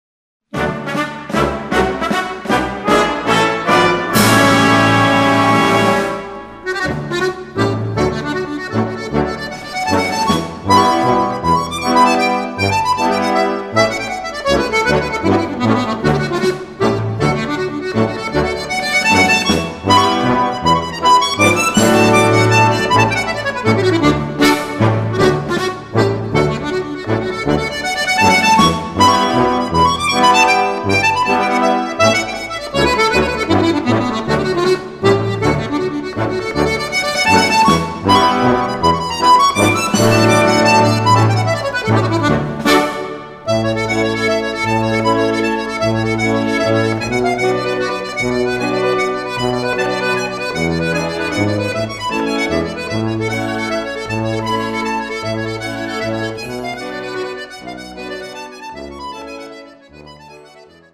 Solo für Akkordion
Besetzung: Blasorchester